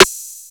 Antidote Snare.wav